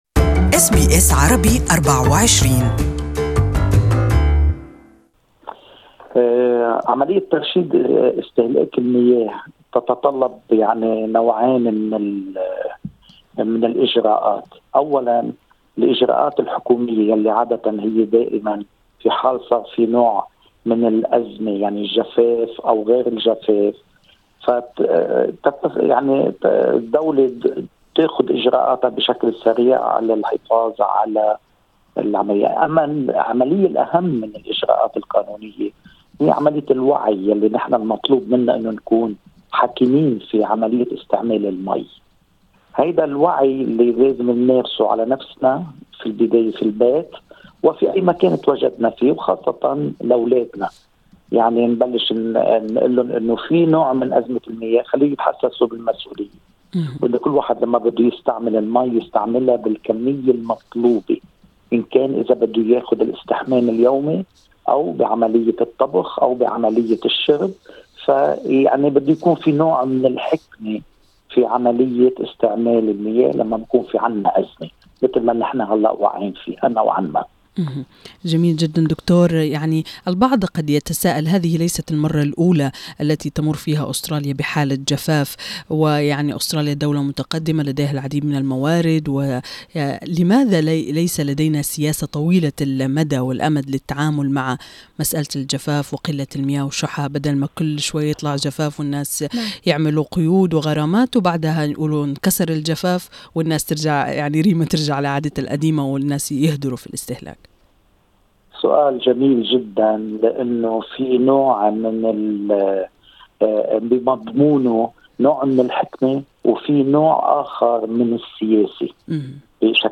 This interview is only available in Arabic.